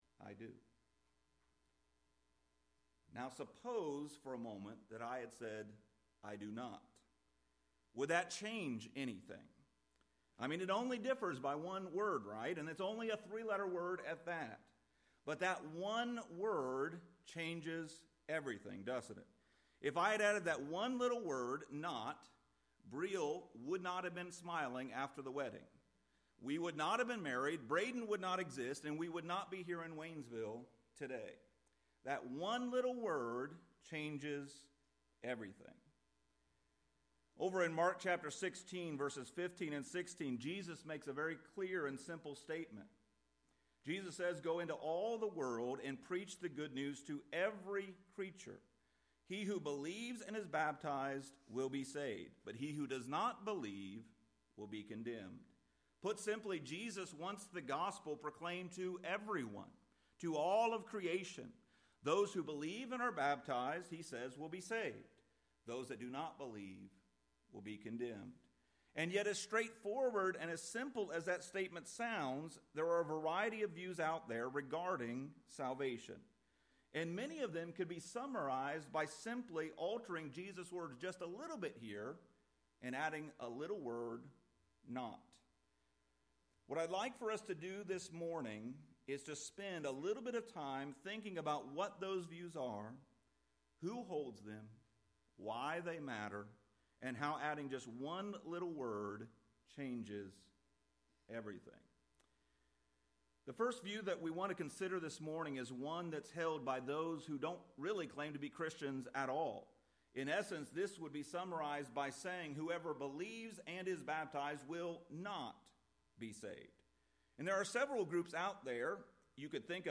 Lesson Recording: